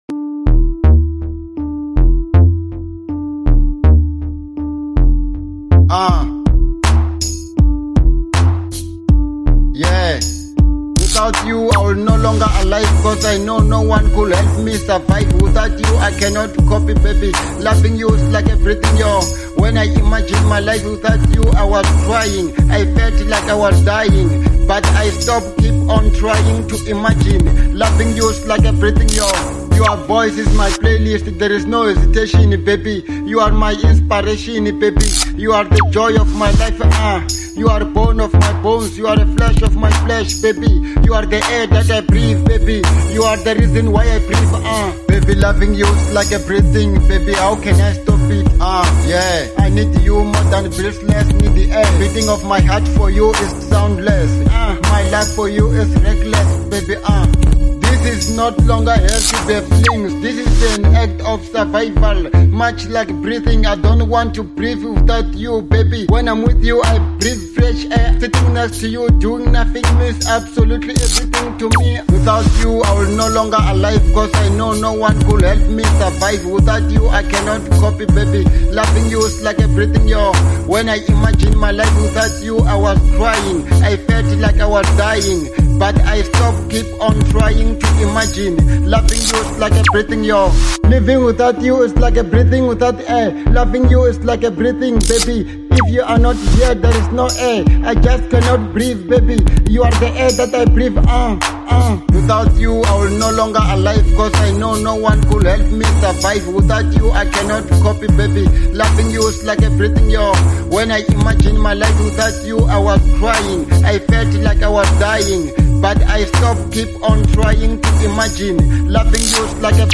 02:05 Genre : Hip Hop Size